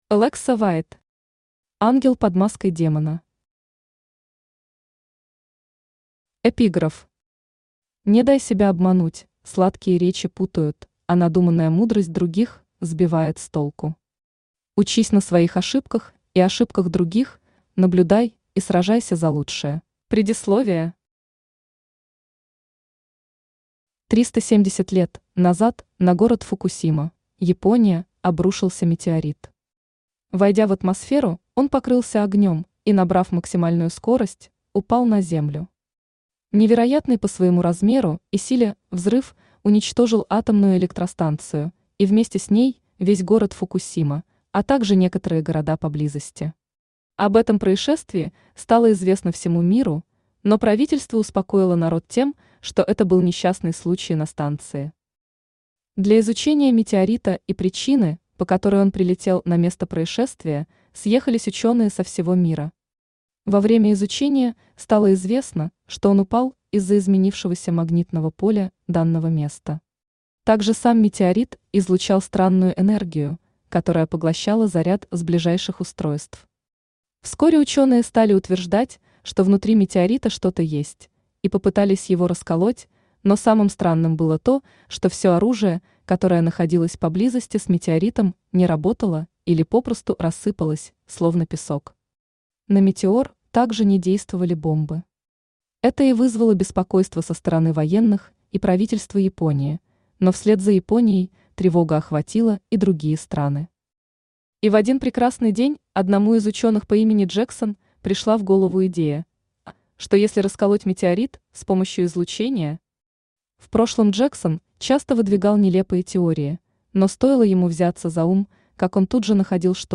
Аудиокнига Ангел под маской демона | Библиотека аудиокниг
Aудиокнига Ангел под маской демона Автор Alexa White Читает аудиокнигу Авточтец ЛитРес.